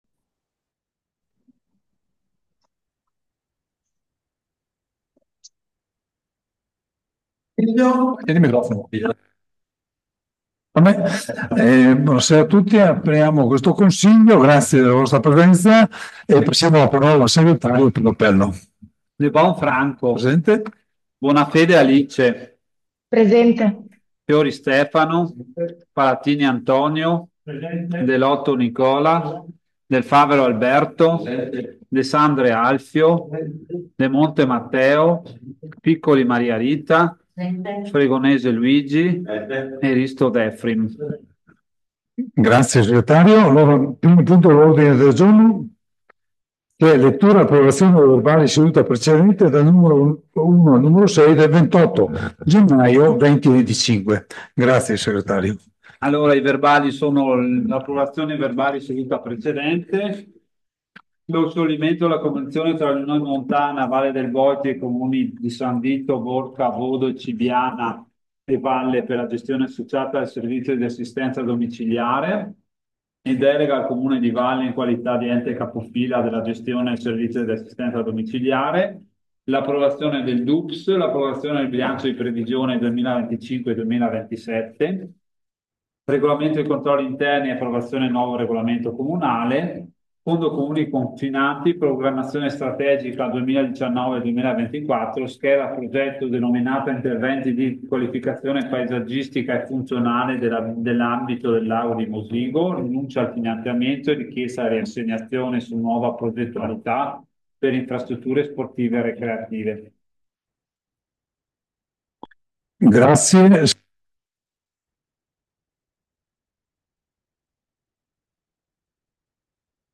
Registrazione audio Consiglio Comunale